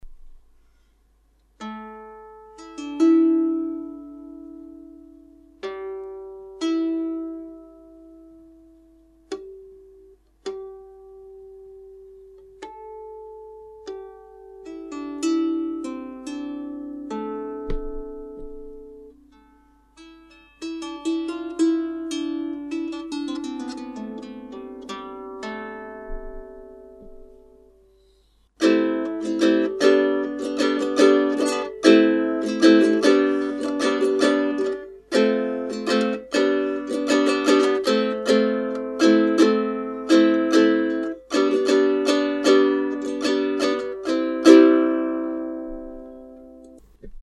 Итак, наконец-то я приобрёл нормальную лиру. Первая в нерабочем состоянии лежит на антресолях, вторую осваиваю пока Вложения IMG_1011.jpg 158 KB · Просмотры: 298 first_lyre.mp3 first_lyre.mp3 1,1 MB · Просмотры: 1.114